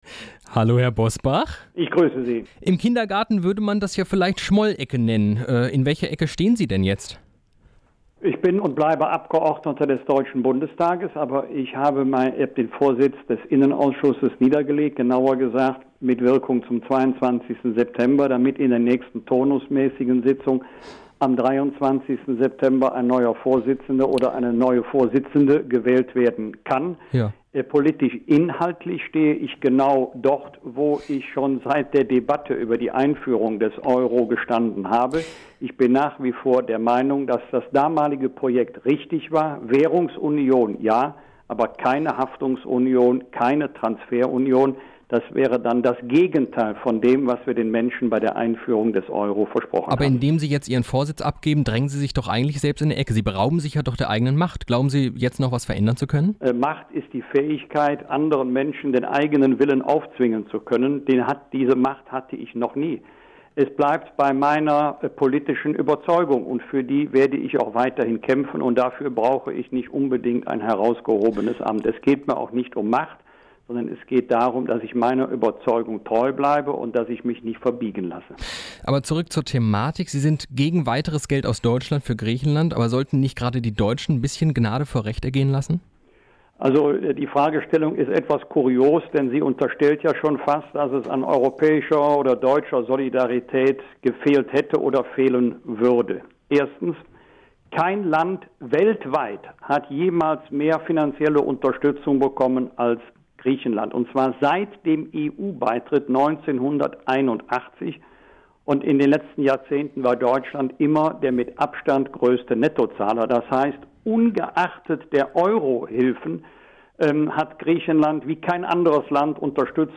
Interview: Wolfgang Bosbach zur Griechenlandkrise